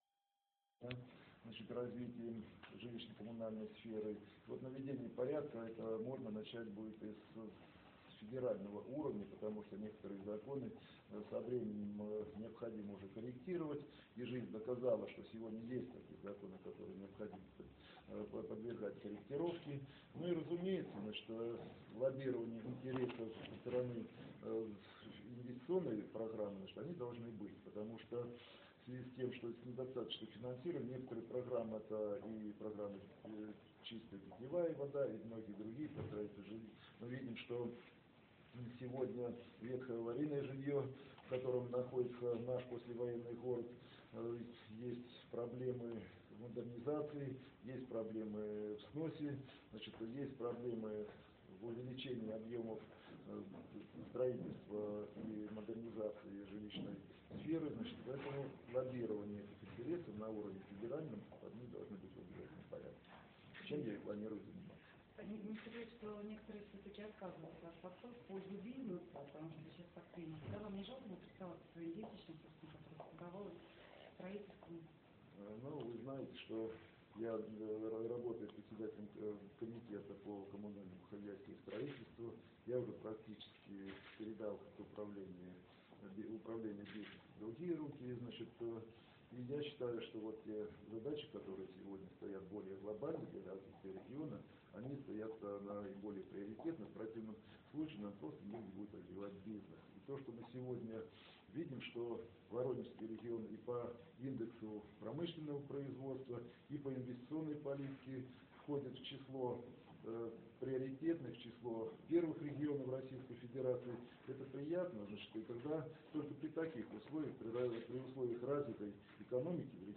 Фрагмент выступления Сергея Лукина (аудио)